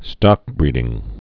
(stŏkbrēdĭng)